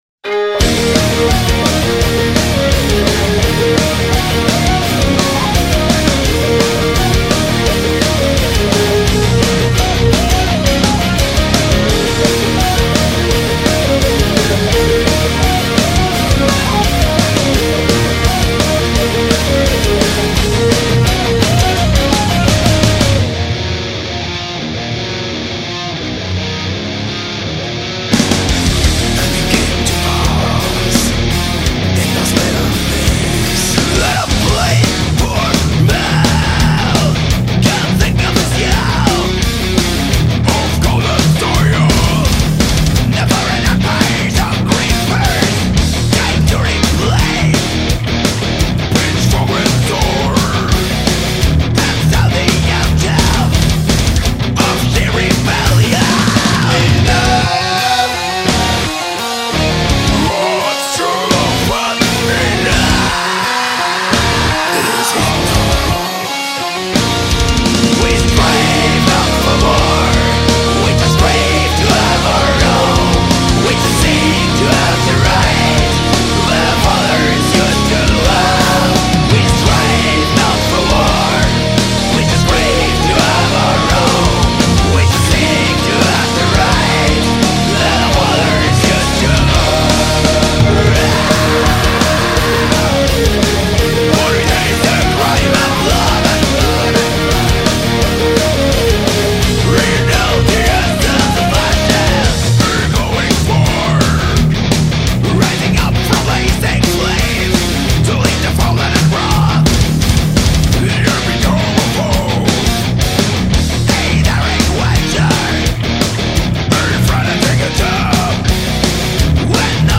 Celtic metal.